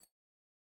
sfx-exalted-hub-banner-button-hover.ogg